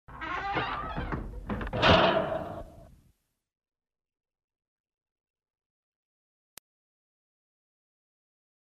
PUERTA QUE SE CIERRA PUERTA CERRADA MEZCLA
EFECTO DE SONIDO DE AMBIENTE de PUERTA QUE SE CIERRA PUERTA CERRADA MEZCLA
PUERTA_QUE_SE_CIERRA_-_puerta_cerrada_mezcla.mp3